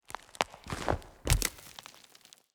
SFX_Root_Attack_Vesna.wav